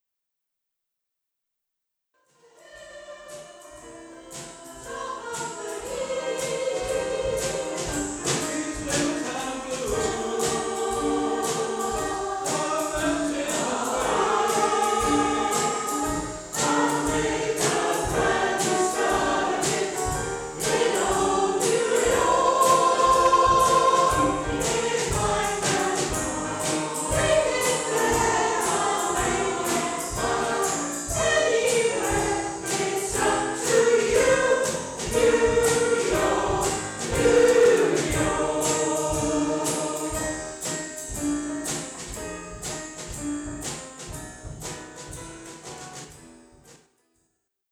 We are one of the largest mixed gender choirs in the area
A selection of audio excerpts from various performances by the Barry Community Choir, recorded at venues across South Wales.
New-York-LIVE-1.wav